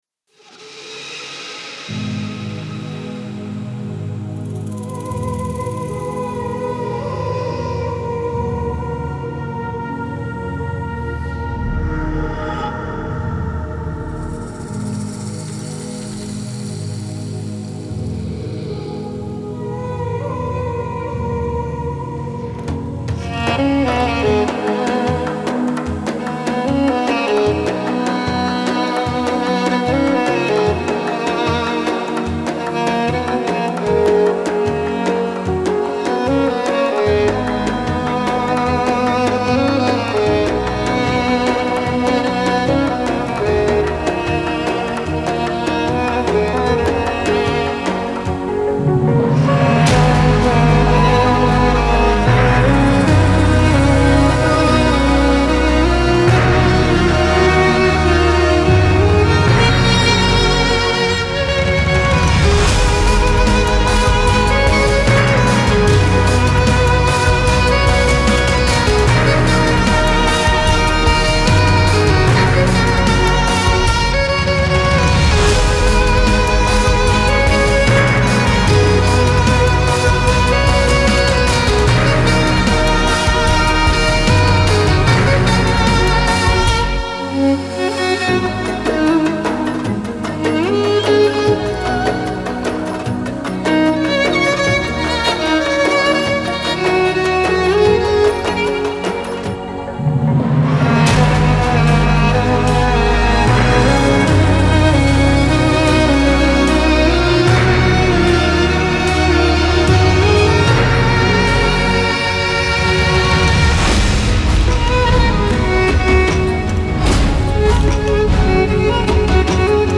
Classical Crossover
ویولن الکترونیک